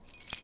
R - Foley 9.wav